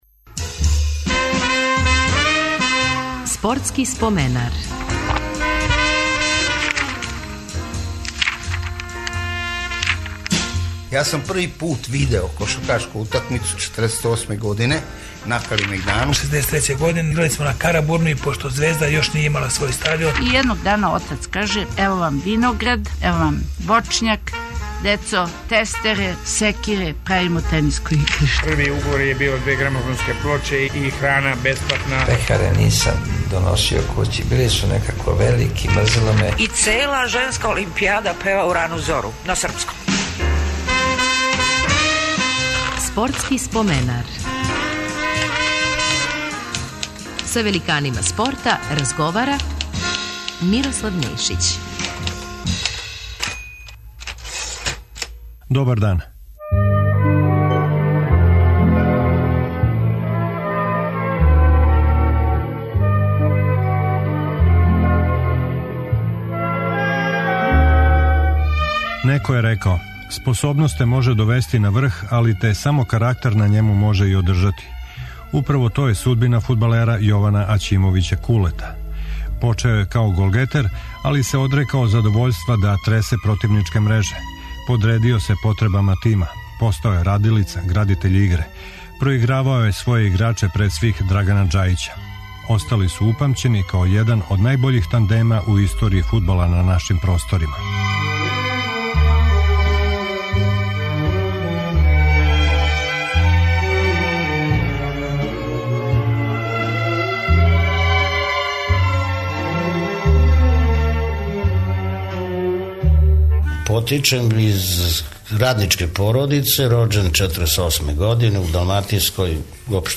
Гост ће нам бити фудбалер Јован-Куле Аћимовић.